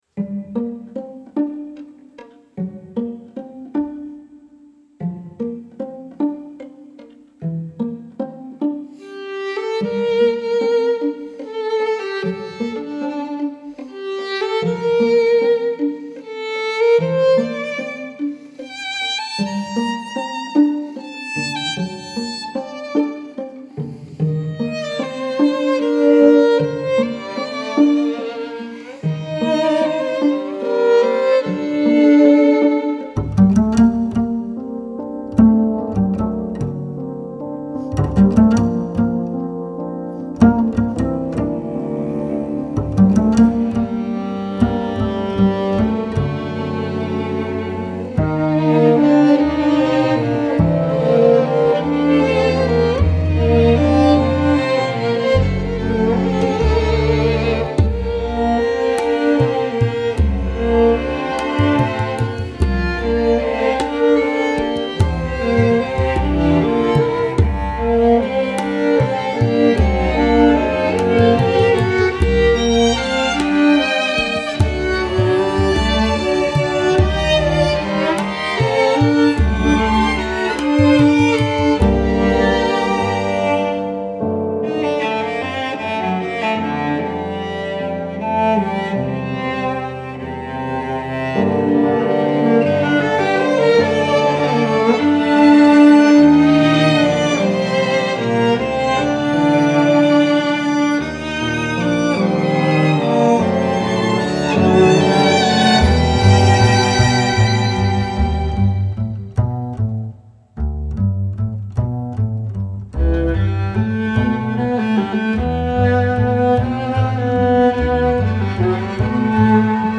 Genre: Chillout, Downtempo, Ambient.